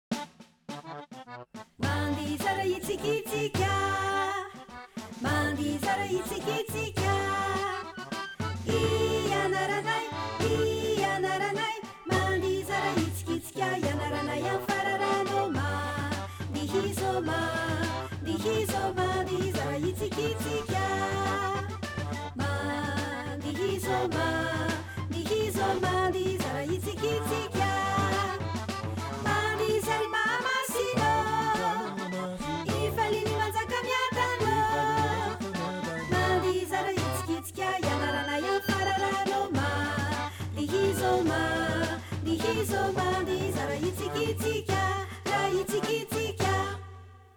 Livre sonore cartonné avec 6 comptines traditionnelles malgaches, illustrées et traduites.